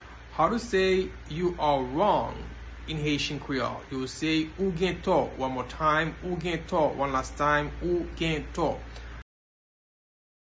Pronunciation:
You-are-wrong-in-Haitian-Creole-Ou-gen-to-pronunciation-by-a-Haitian-Creole-teacher.mp3